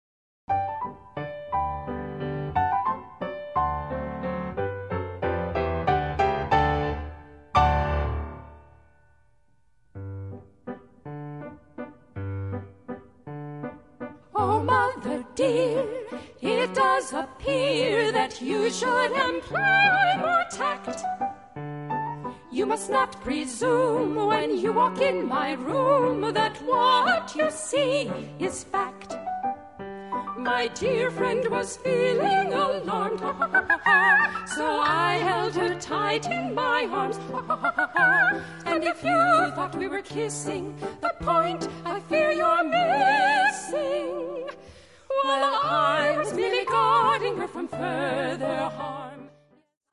--comedy music parody